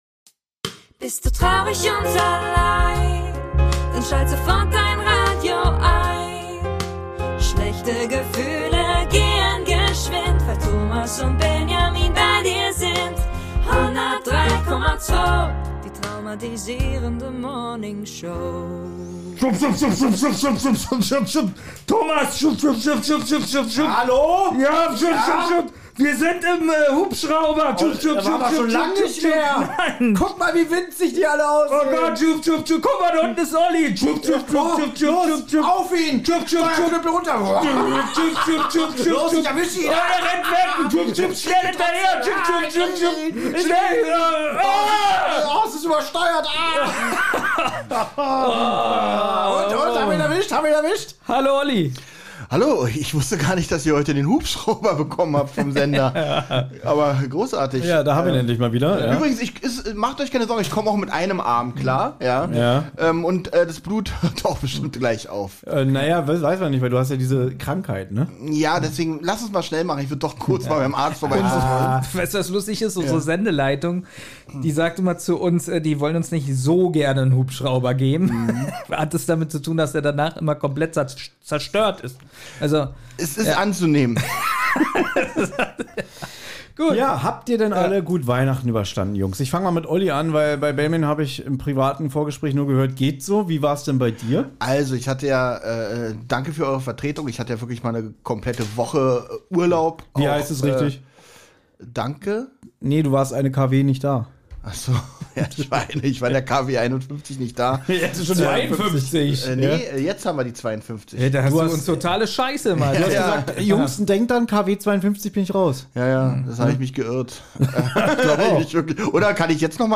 Umso schöner, dass es alle 3 Podcaster nochmal geschafft haben, sich zusammen zu setzen und gemeinsam eine Morningshow zu bestreiten! Wir reden darüber, wie wir die Feiertage verbracht haben und außerdem habt ihr uns gesummte Weihnachtslieder eingesandt, die wir erkennen sollen.